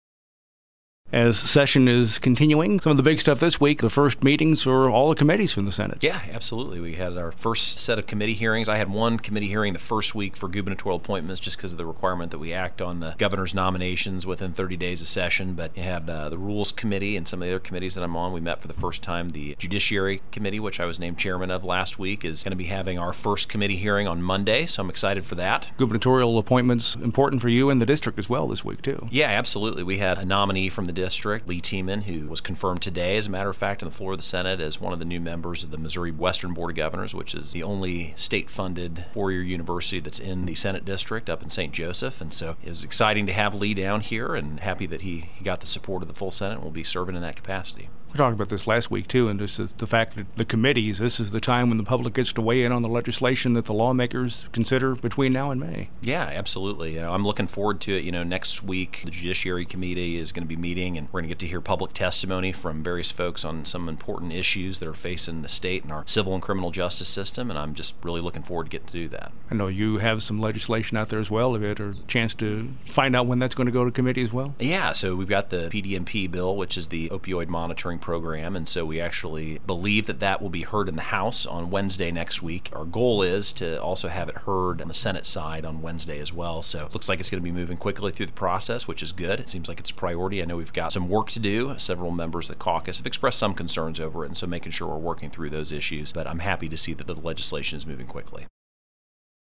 JEFFERSON CITY — State Sen. Tony Luetkemeyer, R-Parkville, discusses the beginning of committee hearings in the Missouri Senate, a gubernatorial appointee he sponsored, plus key legislation set for committee time next week.
1. Senator Luetkemeyer says committee hearings are now under way in the Missouri Senate.